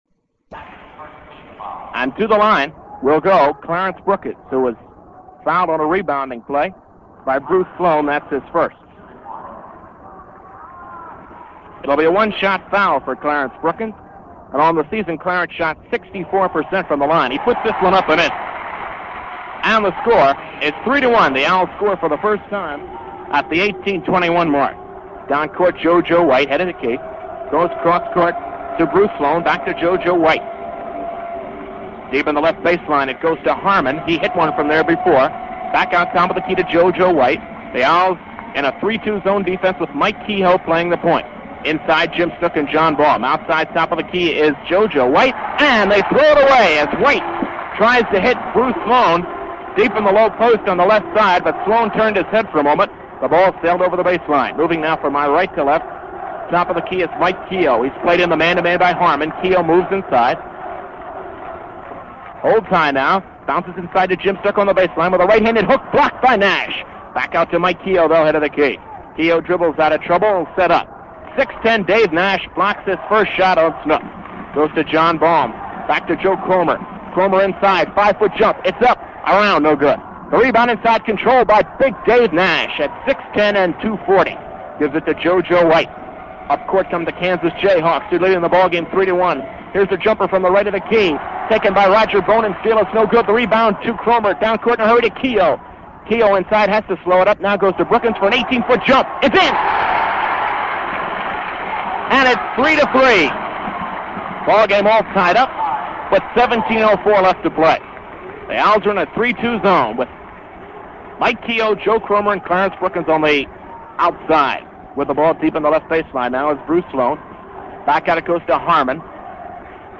Here's a segment from a Temple Basketball game. Not just any game, but an appearance in the prestigious NIT (National Invitational Tournament) against Kansas at the then "new" Madison Square Garden.
doing play-by-play